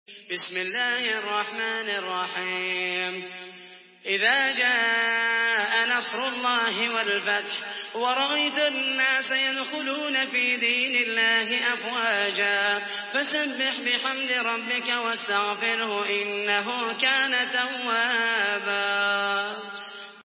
Surah আন-নাসর MP3 by Muhammed al Mohaisany in Hafs An Asim narration.
Murattal Hafs An Asim